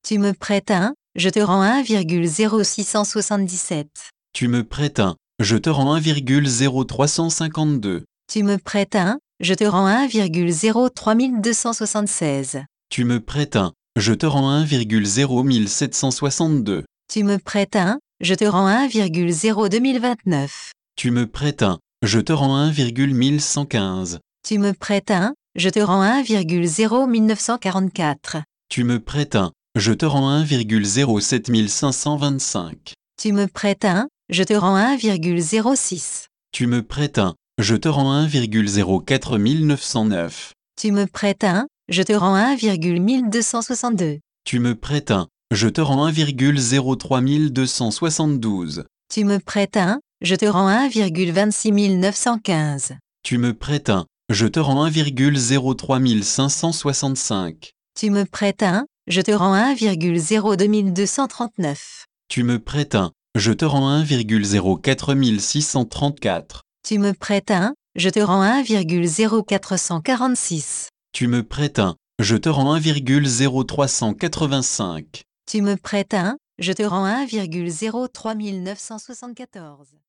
La pièce Panoramique des obligations se présente sous la forme d’un casque sans fil posé au mur. On entend deux voix, celle d’une femme et celle d’un homme qui se renvoient tous deux, à la manière d’un ping-pong stéréophonique, les taux d’intérêt de l’ensemble des dettes souveraines mondiales au lendemain du référendum grec du 5 juillet 2015.